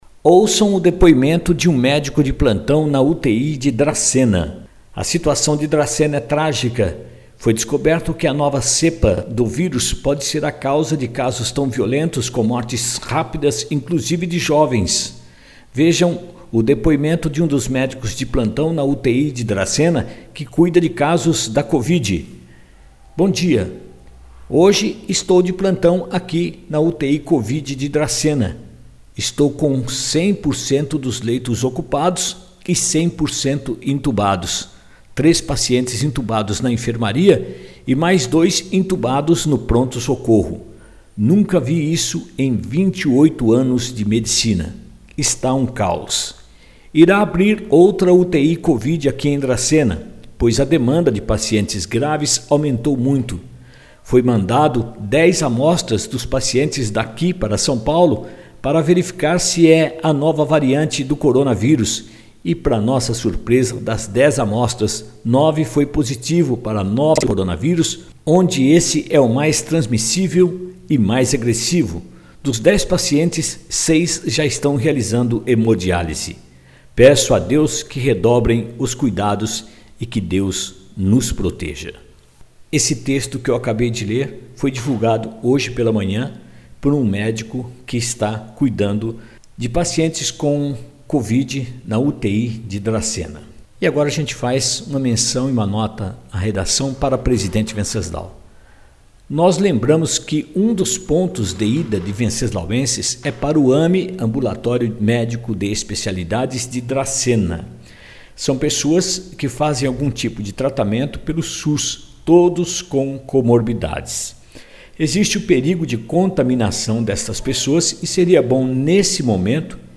Vejam o depoimento de um médico de plantão na UTI de Dracena